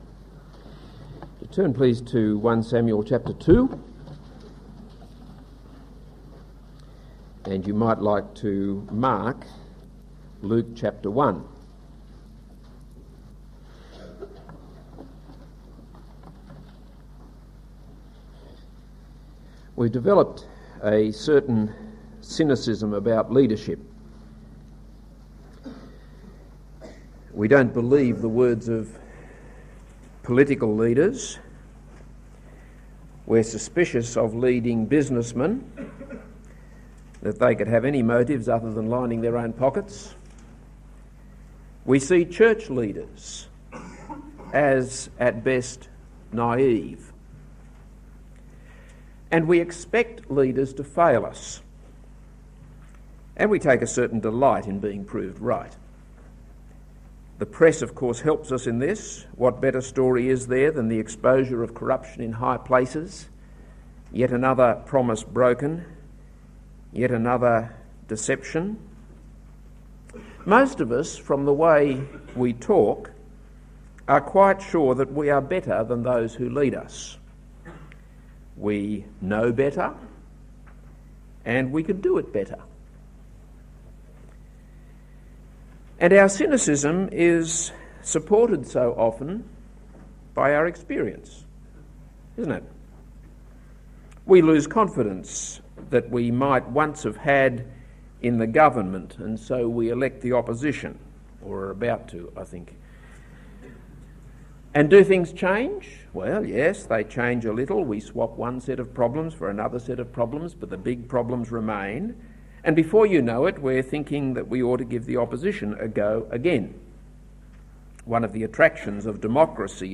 This is a sermon on 1 Samuel 2:1-10; Luke 1:46-55.